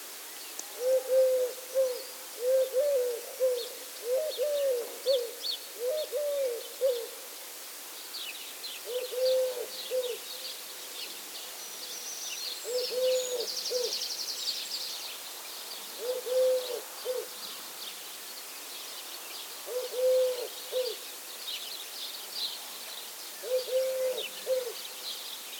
Streptopelia decaocto - Collared dove - Tortora dal collare
- IDENTIFICATION AND BEHAVIOUR: A pair of doves is perched on a barn roof. The male performs the courtship display and calls. - POSITION: Poderone near Magliano in Toscana, LAT.N 42° 36'/LONG.E 11° 17'- ALTITUDE: +130 m. - VOCALIZATION TYPE: full song.
Background: Italian sparrow and Cirl bunting.